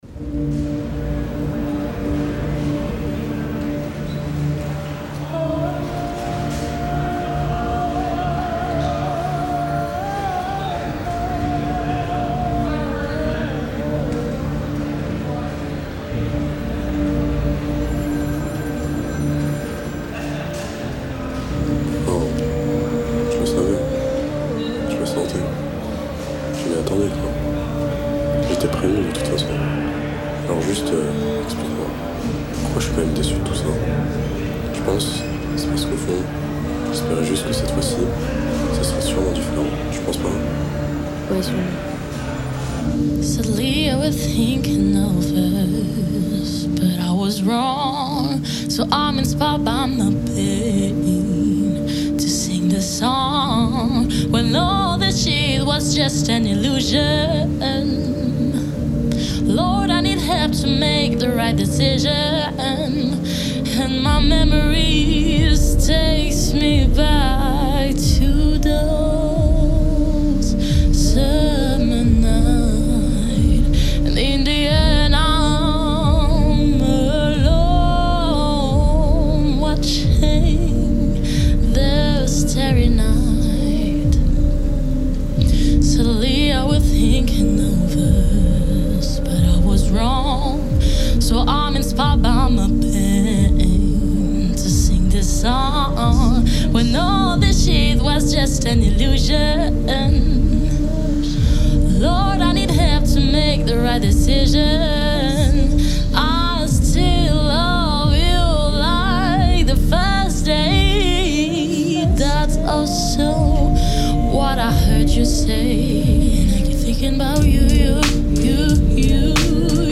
On a décidé de faire des musiques ambiantes qui pourraient coller avec un court métrage ou bien un film.